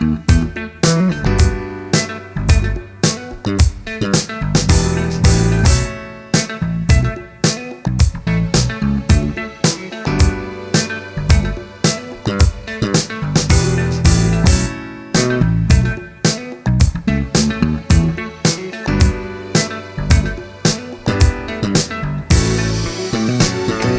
no Backing Vocals Soul / Motown 3:59 Buy £1.50